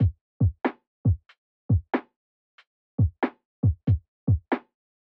标签： 93 bpm Hip Hop Loops Drum Loops 889.29 KB wav Key : Unknown
声道立体声